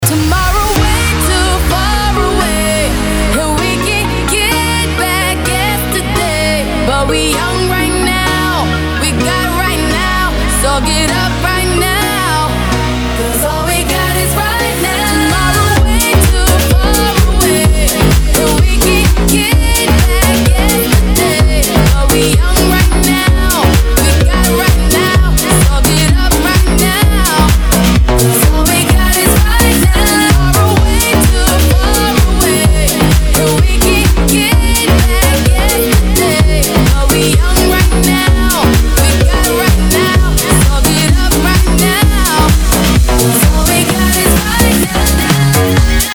• Качество: 320, Stereo
Ремикс